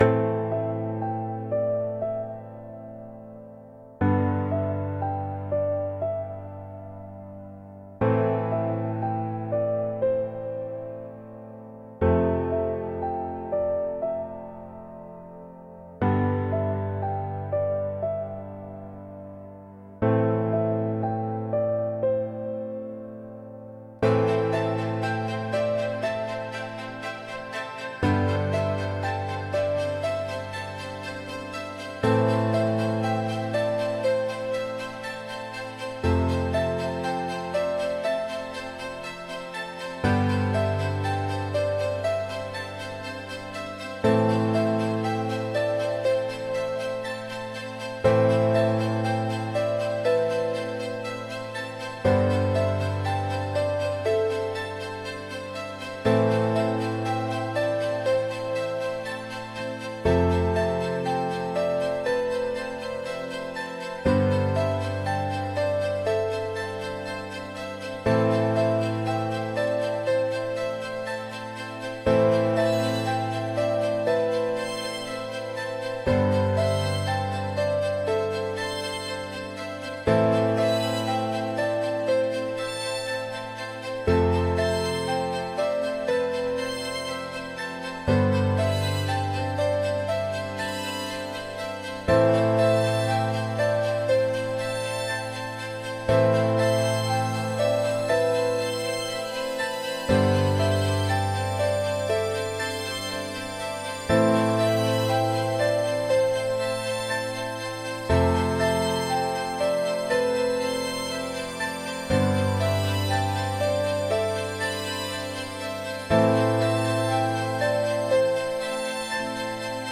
Instrumental: (Remastered)